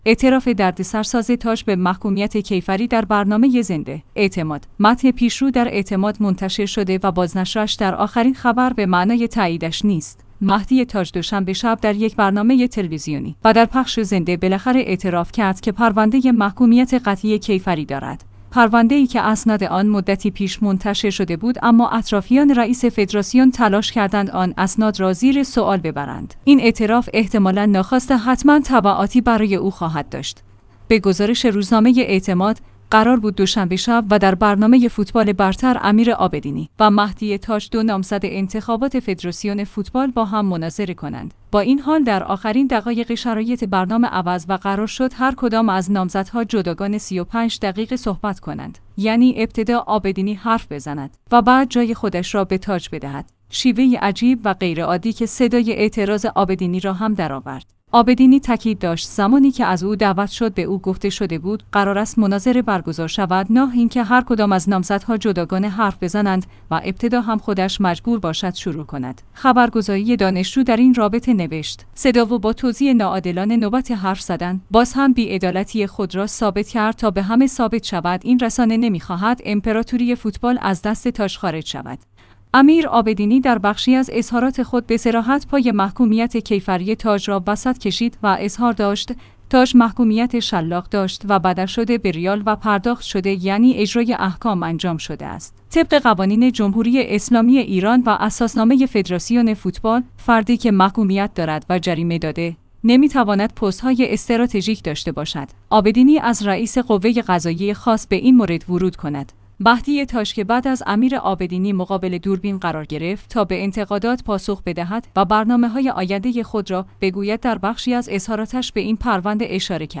اعتماد/متن پیش رو در اعتماد منتشر شده و بازنشرش در آخرین خبر به معنای تاییدش نیست مهدی تاج دوشنبه شب در یک برنامه تلویزیونی و در پخش زنده بالاخره اعتراف کرد که پرونده محکومیت قطعی کیفری دارد.